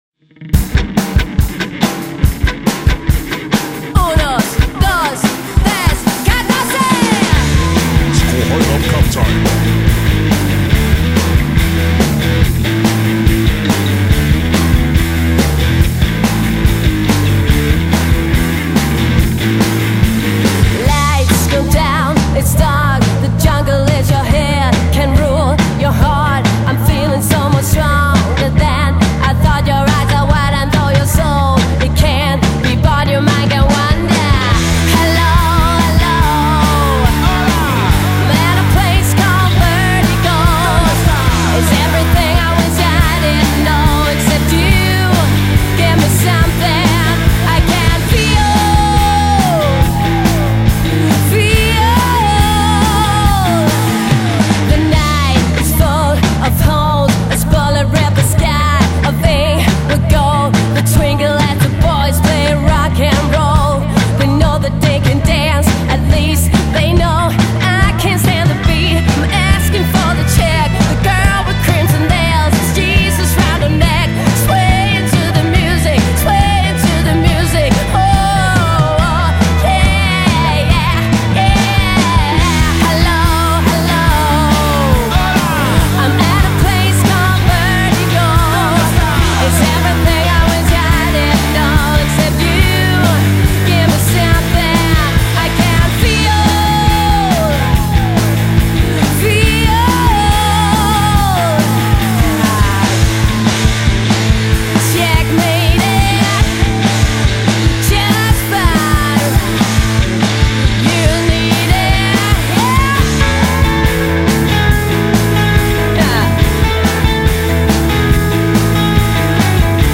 Det vil sige, bas, trommer, og nogle kor stemmer.